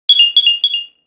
Alert_Attention.wma